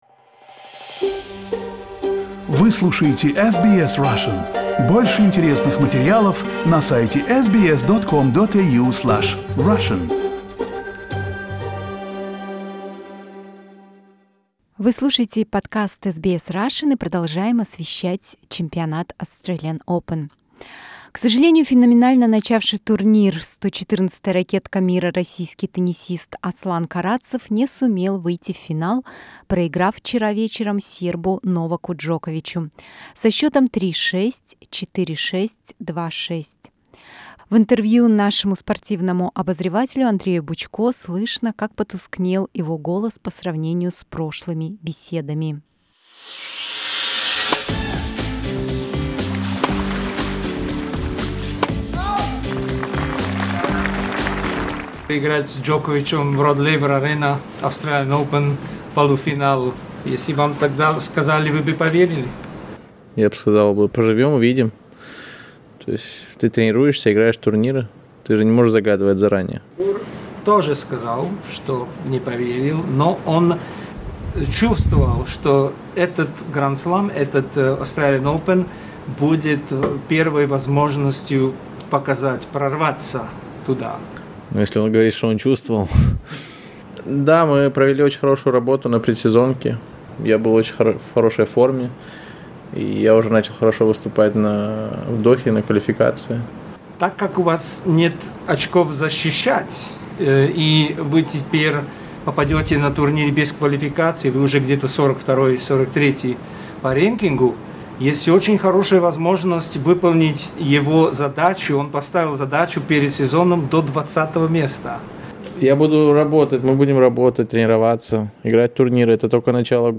Interview with Aslan Karatsev after losing the Australian Open semi-finals
Interview with a Russian tennis player Aslan Karatsev after losing the Australian Open semi-finals.